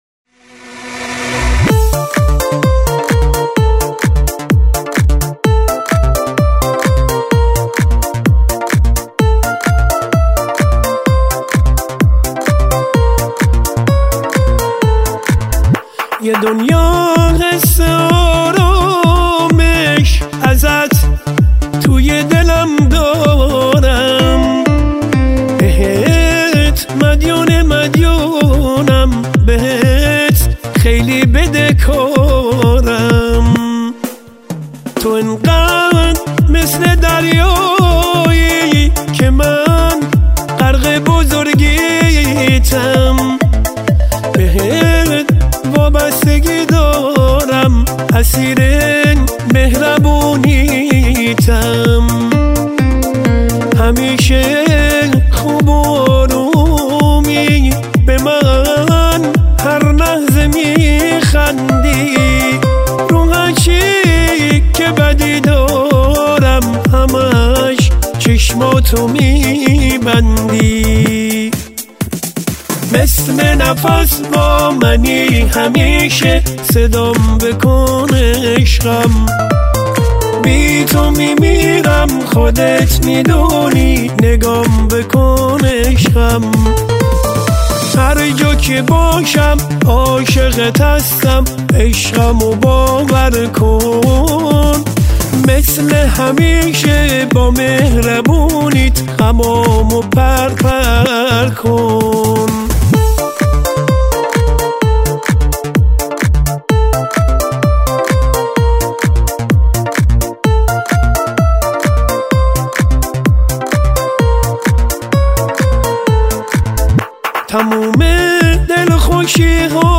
مثل همیشه عالی وبسیار با احساس
بسیارصدای گیرای داره